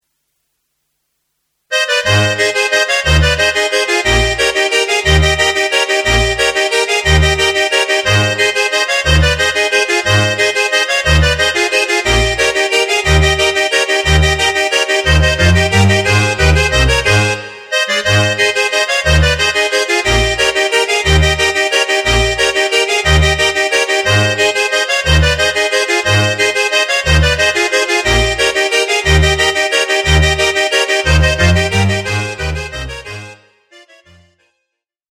Ab-Dur